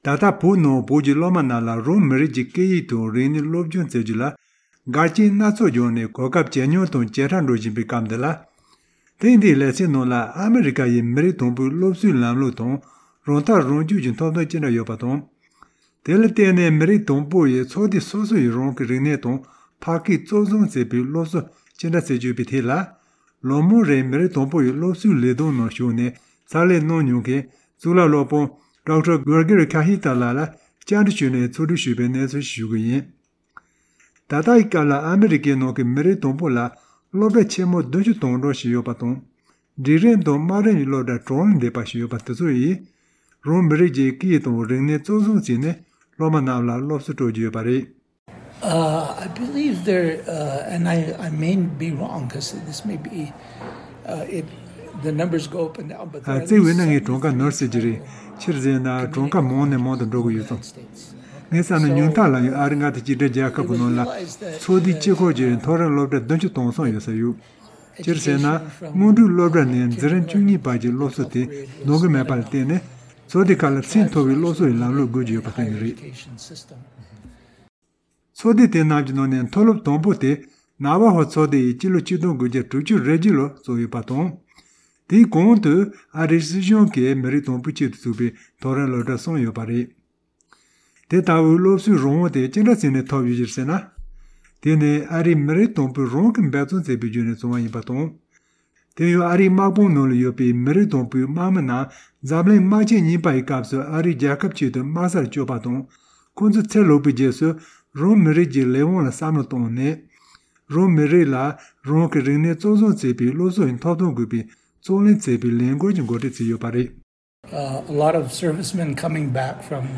བཅར་འདྲི་ཞུས་ནས་ཕྱོགས་བསྒྲིགས་ཞུས་པ་ཞིག་